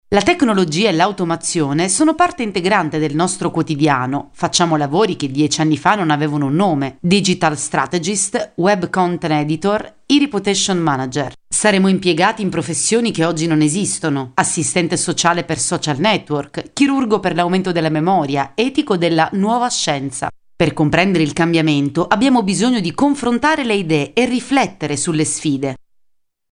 特点：轻快活力 大气浑厚 稳重磁性 激情力度 成熟厚重
意大利男女样音